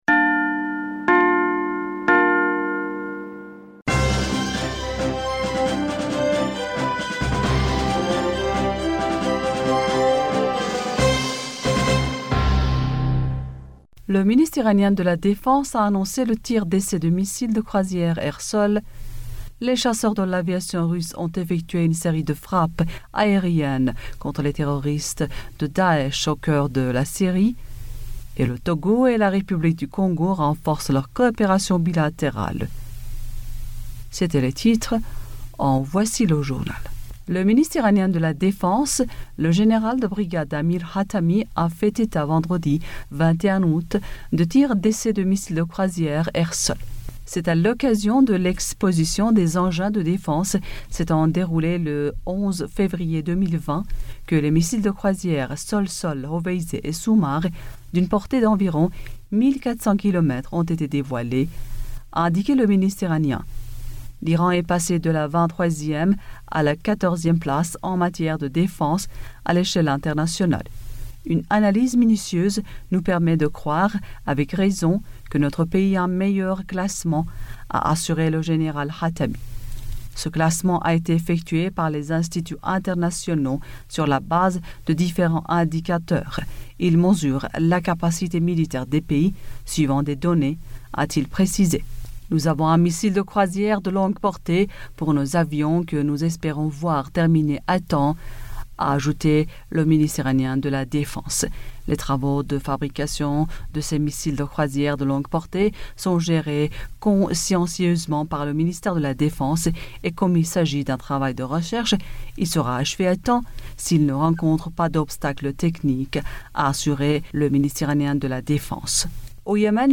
Bulletin d'information du 22 Aout 2020